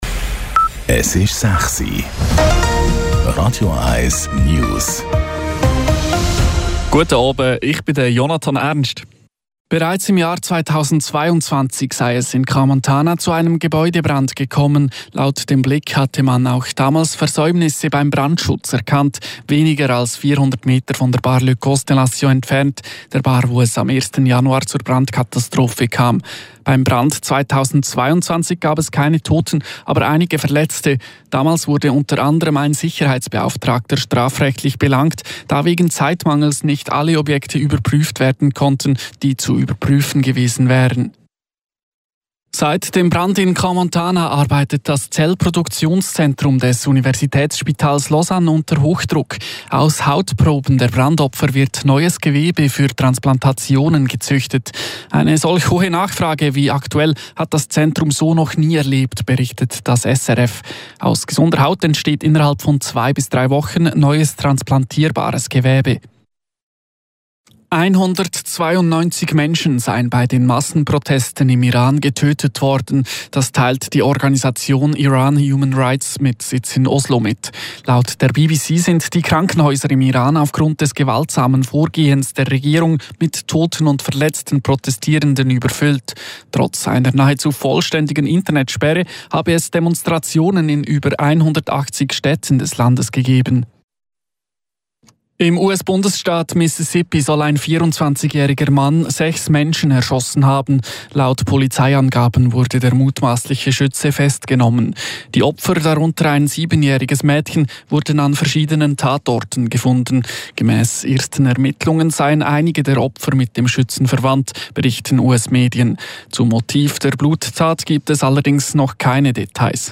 Die aktuellsten News von Radio 1 - kompakt, aktuell und auf den Punkt gebracht.